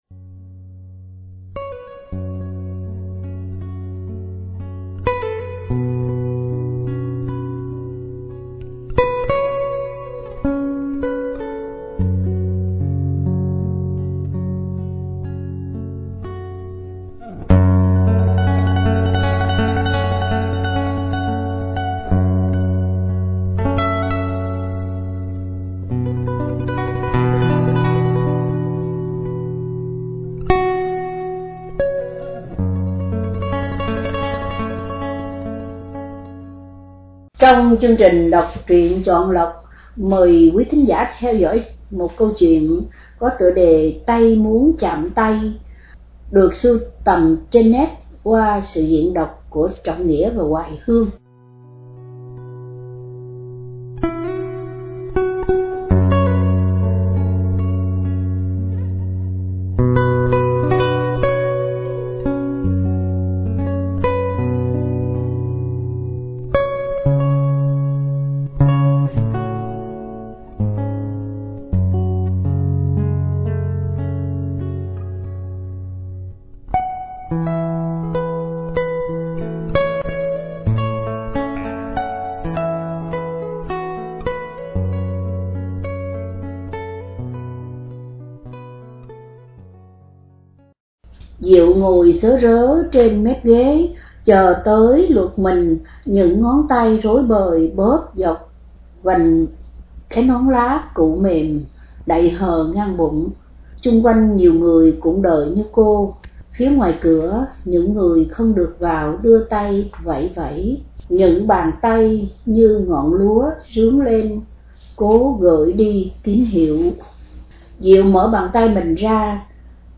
Đọc Truyện Chọn Lọc – Tay Muốn Chạm Tay – Sưu Tầm – Radio Tiếng Nước Tôi San Diego